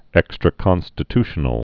(ĕkstrə-kŏnstĭ-tshə-nəl, -ty-)